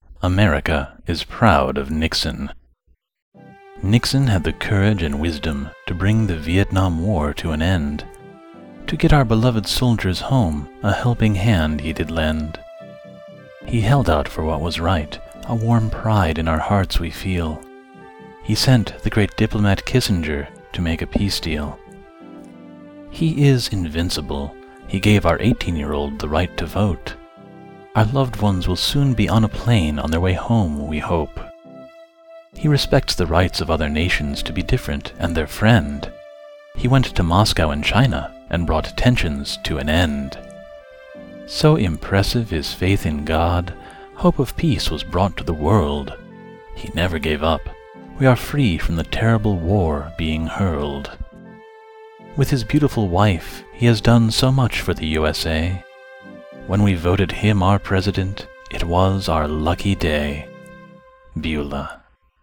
Here is my rendition of her poem, “